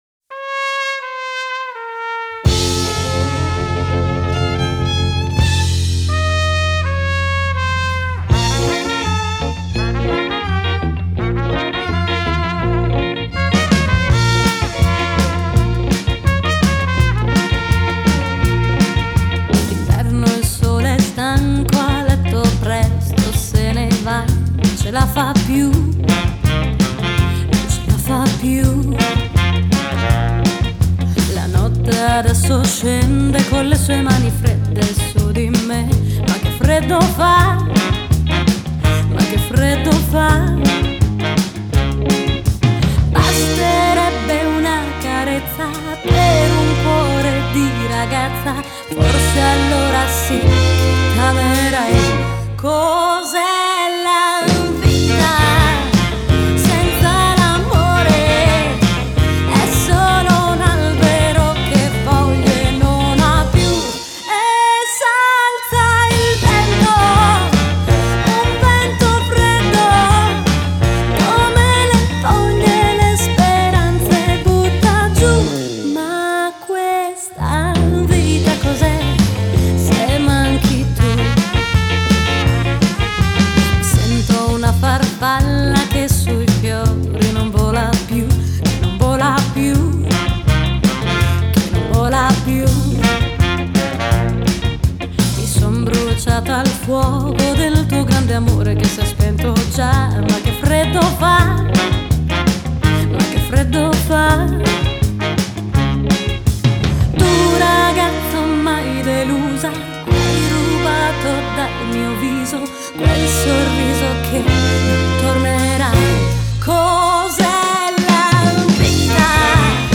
Revival ‘50 ‘60 Italia America